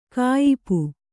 ♪ kāyipu